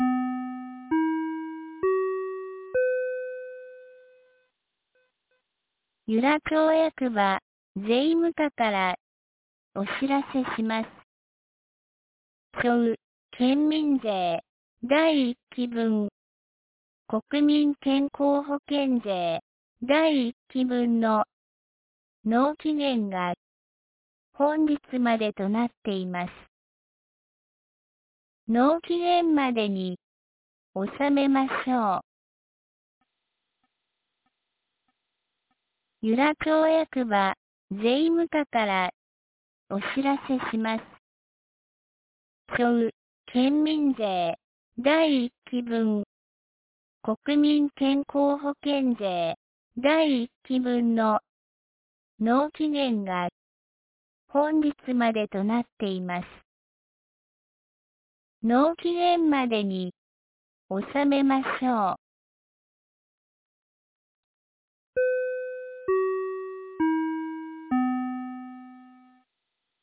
2025年06月30日 07時51分に、由良町から全地区へ放送がありました。